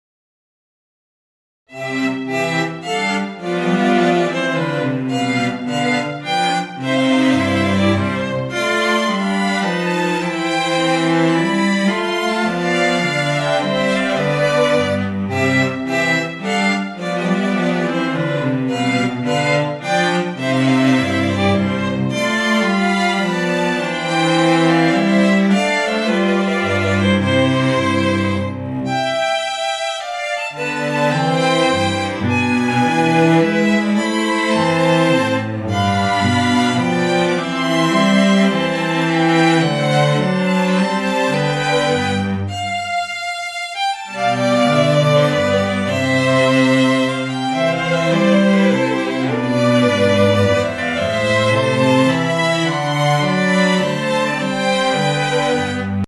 baroque" Voici un exemple peu travaillé sur les nuances, mais ça fait déjà pas mal l'affaire pour ce style